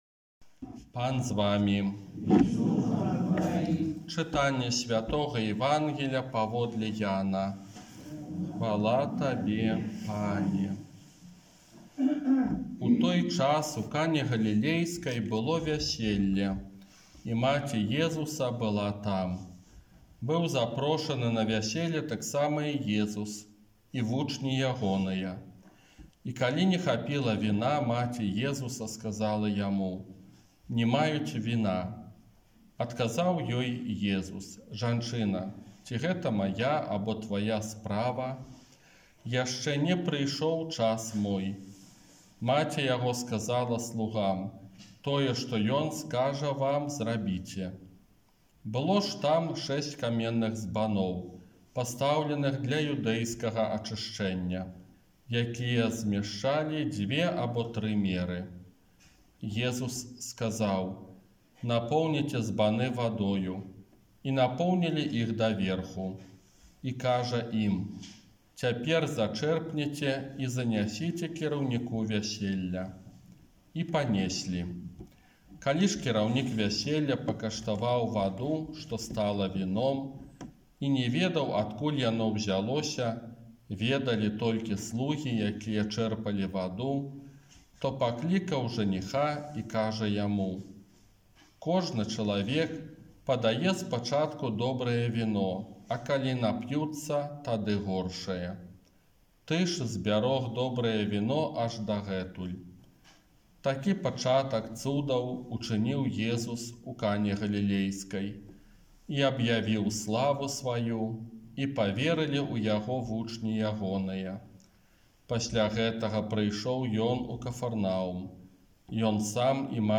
Казанне на другую звычайную нядзелю